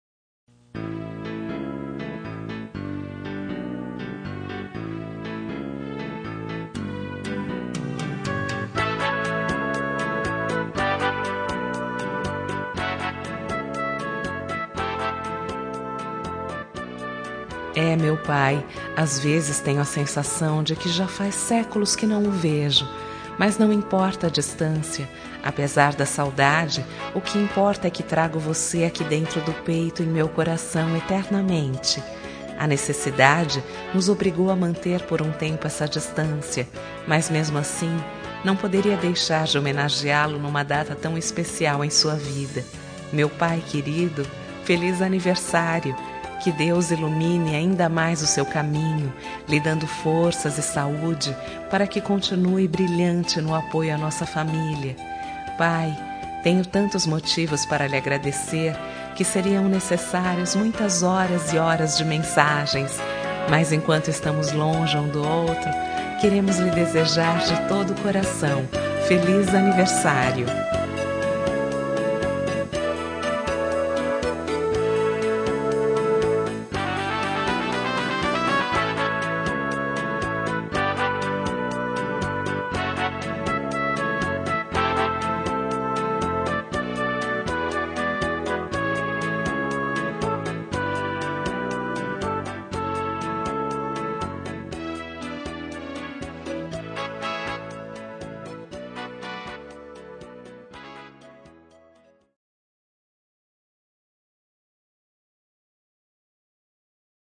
Telemensagem de Aniversário de Pai – Voz Feminina – Cód: 1484 Distante